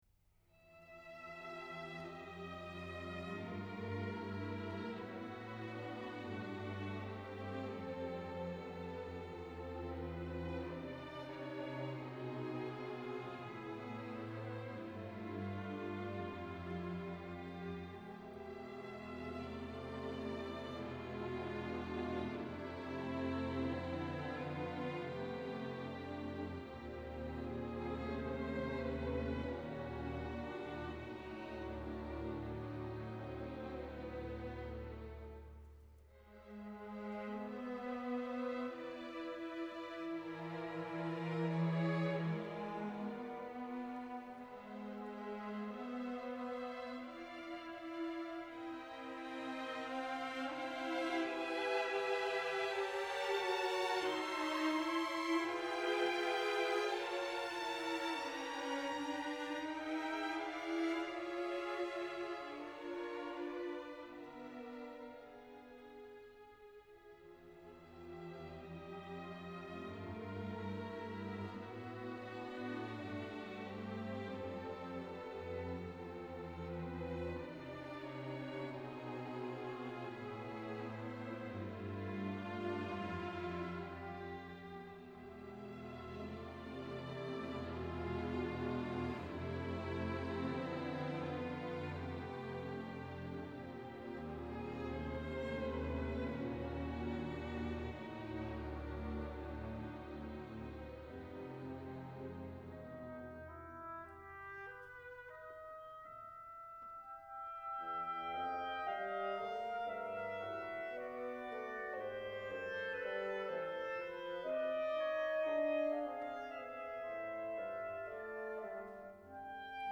Clásica